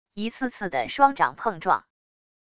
一次次的双掌碰撞wav音频